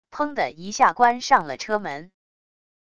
砰的一下关上了车门wav音频